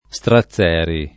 [ S tra ZZ$ ri ]